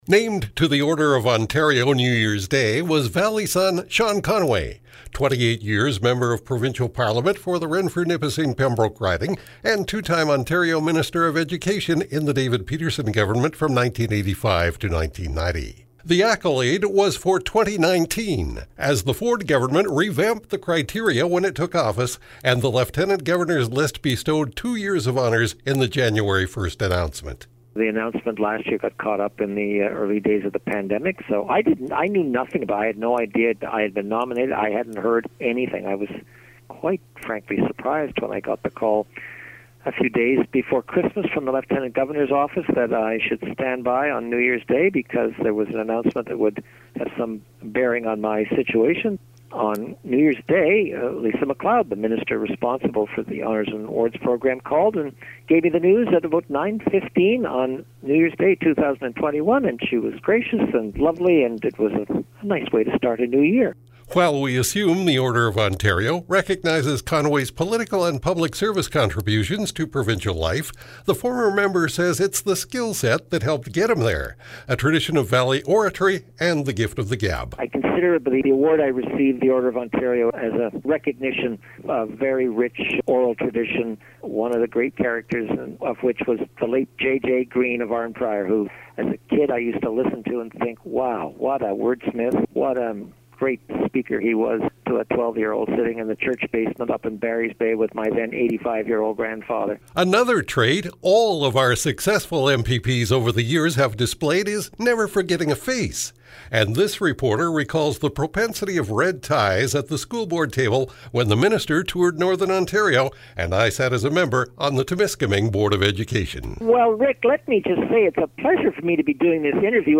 PRODUCED FEATURE (4:25) duplicates above, with narrative
jan-5-2021-sean-conway-order-of-ontario-interview.mp3